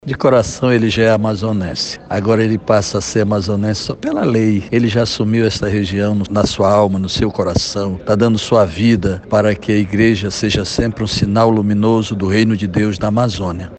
A sessão especial contou com a presença de autoridades civis, representantes da Arquidiocese de Manaus, padres do clero Arquidiocesano, religiosos, agentes de pastorais, serviços e movimentos e a imprensa.